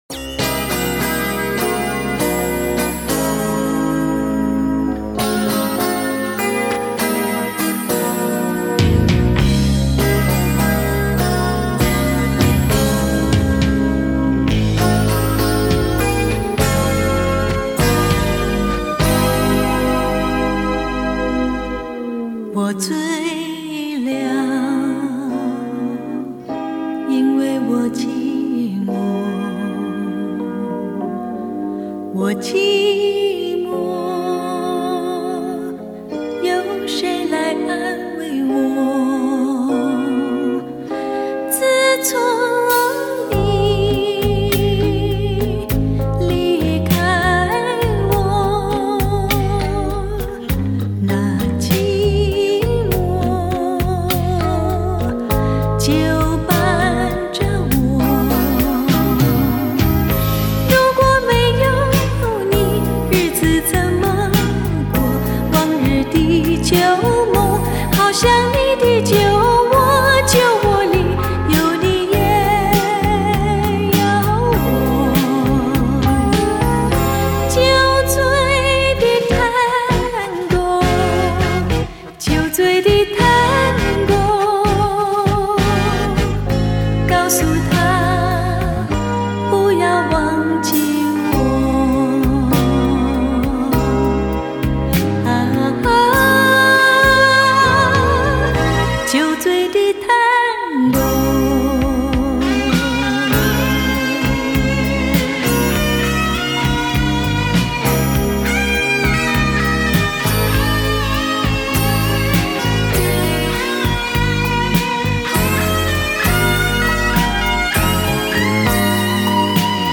水晶般纯净的音质